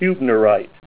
Name Pronunciation: Hubnerite + Pronunciation Synonym: Huebnerite Hubnerite Image Images: Hubnerite Comments: Sharp, dark tabular crystals of hübnerite with pyrite.
HUBNERIT.WAV